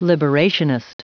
Prononciation du mot liberationist en anglais (fichier audio)
Prononciation du mot : liberationist